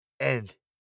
• and（〜と）：ənd → enʔ
🇬🇧発音：and（〜と）